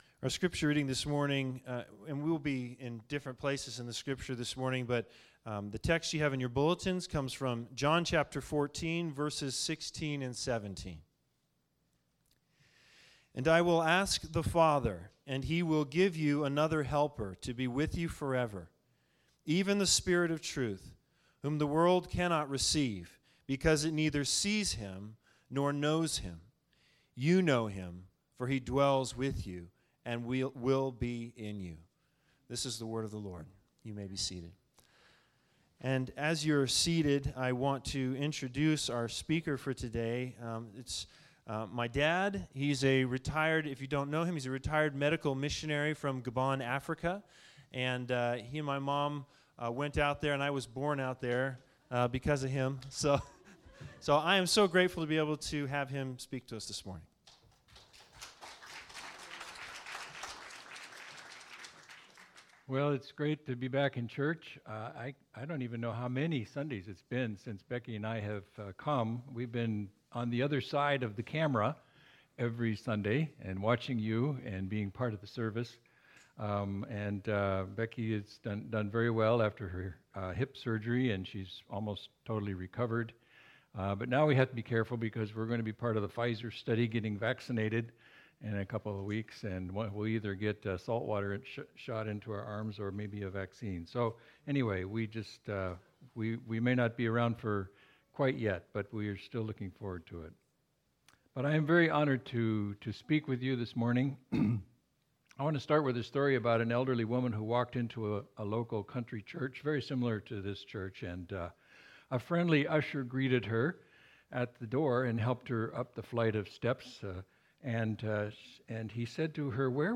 Service Type: Special Sermons